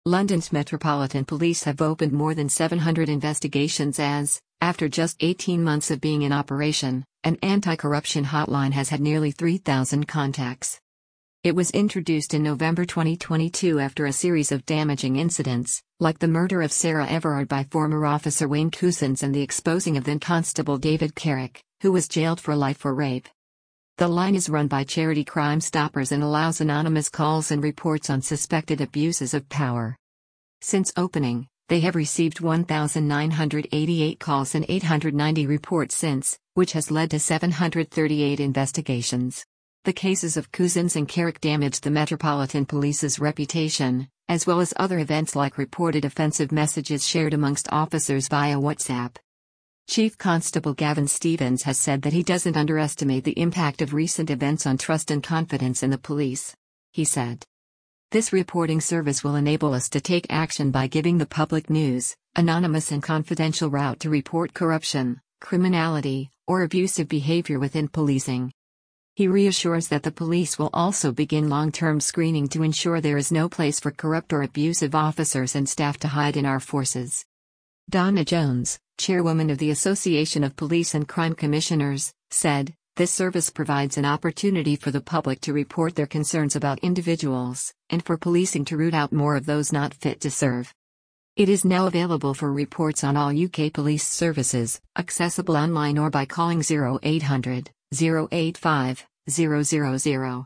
Listen to this article powered by AI.